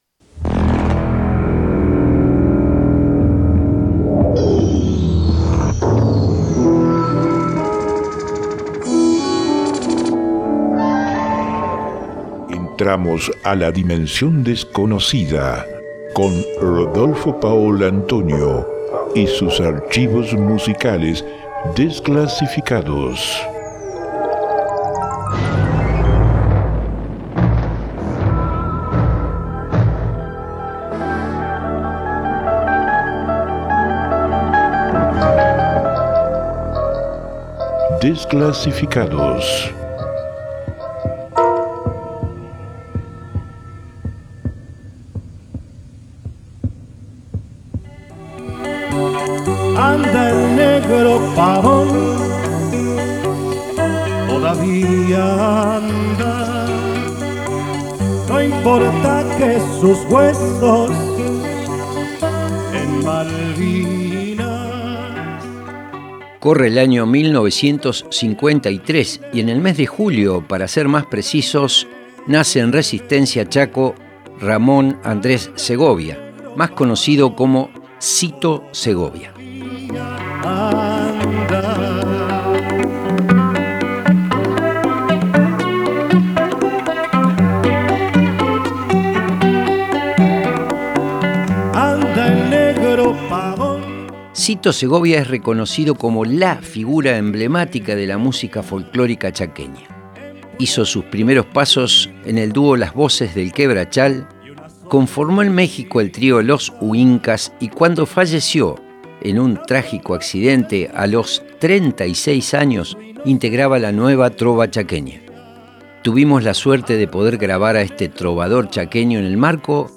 en vivo en el Festival Internacional de Música Popular